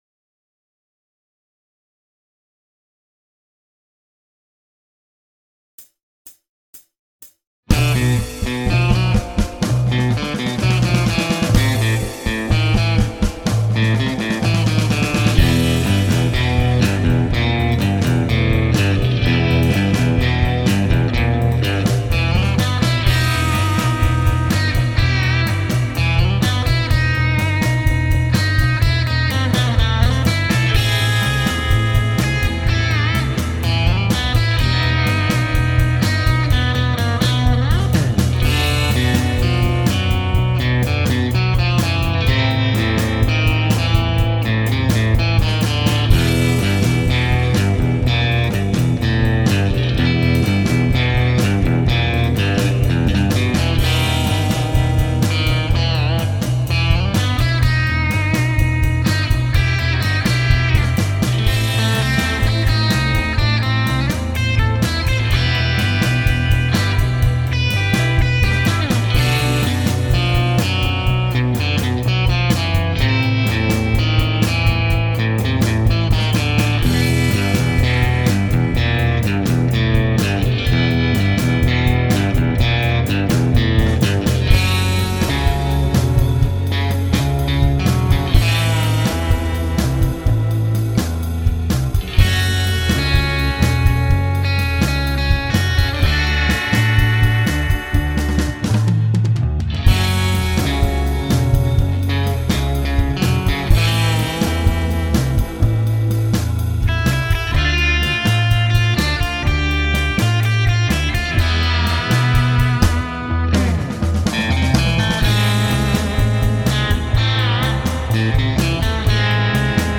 Got some decent drum sounds on this also, I think
Mix #2 with some improved bass and guitar tones
I love spy tunes!
I click a switch and turn each HB into a single coil